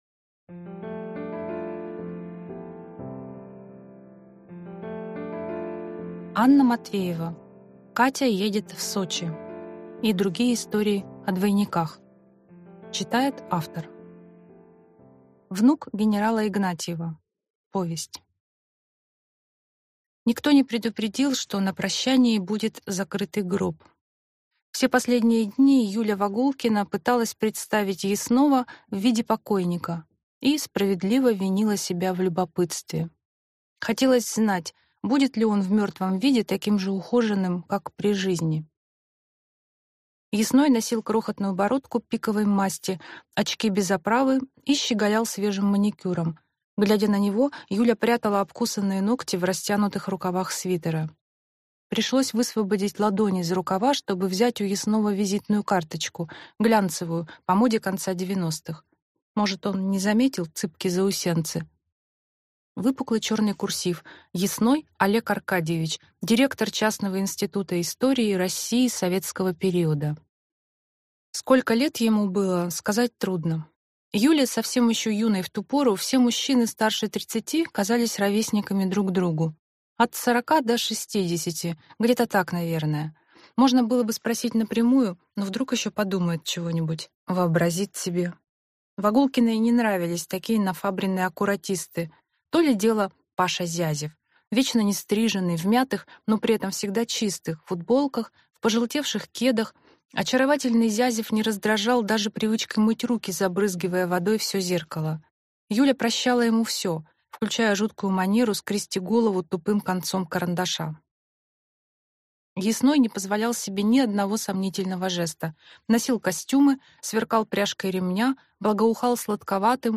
Аудиокнига Катя едет в Сочи. И другие истории о двойниках | Библиотека аудиокниг